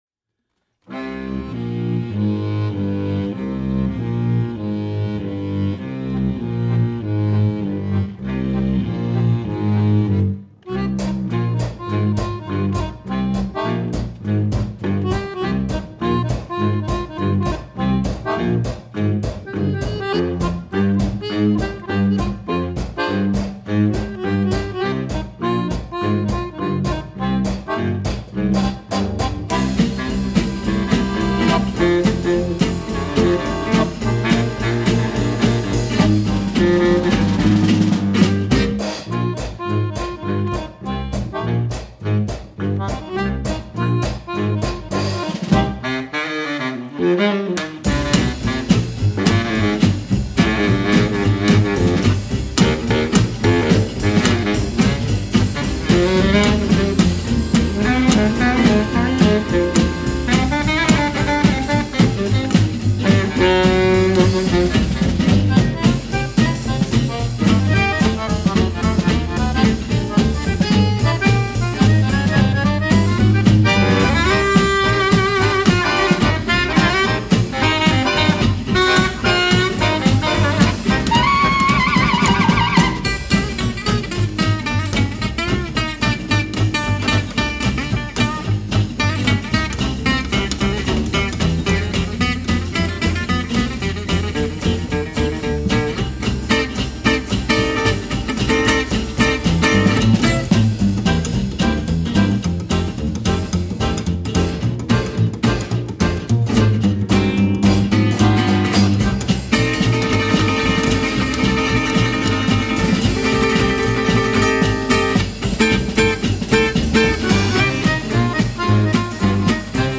a gypsy jazz sextet
the gypsy jazz of 1930's Europe